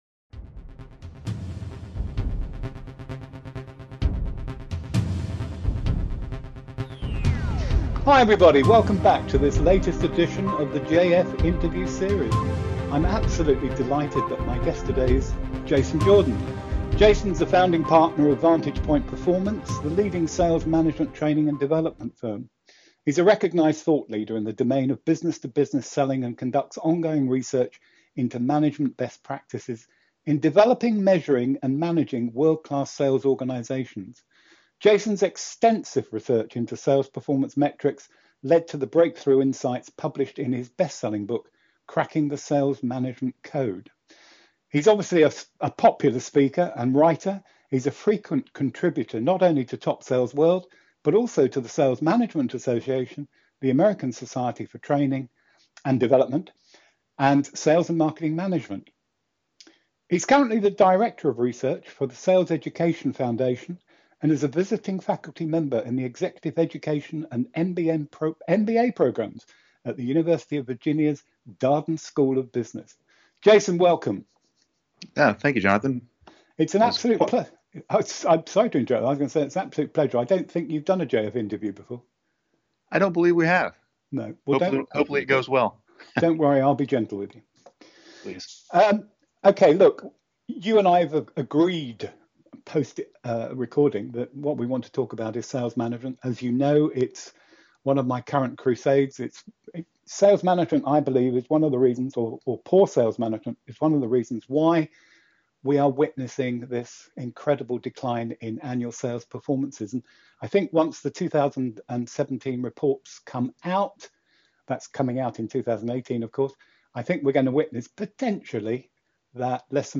Category: Interview, Sales Management